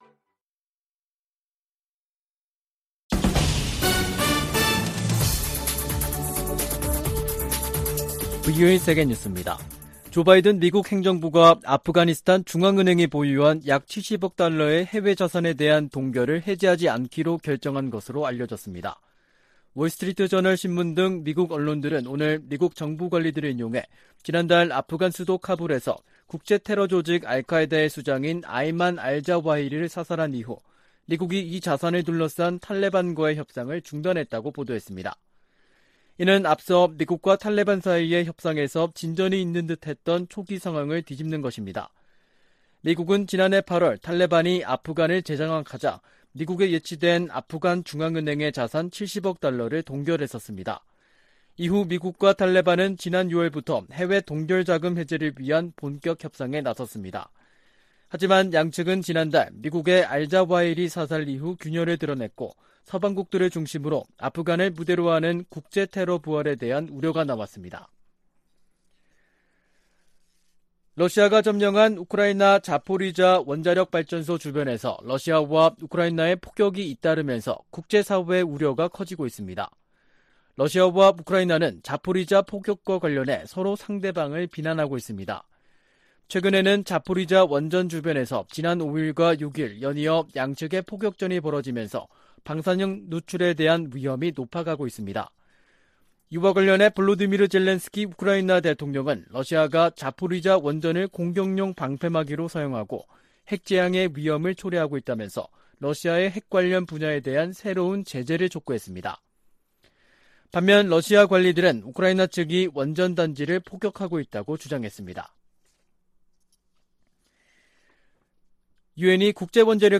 VOA 한국어 간판 뉴스 프로그램 '뉴스 투데이', 2022년 8월 16일 3부 방송입니다. 미국과 한국, 일본, 호주, 캐나다 해군이 하와이 해역에서 ‘퍼시픽 드래곤’ 훈련을 진행했습니다. 미 국무부는 한국 윤석열 대통령이 언급한 ‘담대한 구상’과 관련해 북한과 외교의 길을 모색하는 한국 정부를 강력히 지지한다고 밝혔습니다. 에드 마키 미 상원의원이 한국에서 윤석열 대통령과 권영세 통일부 장관을 만나 동맹 강화 방안과 북한 문제 등을 논의했습니다.